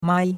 mai1.mp3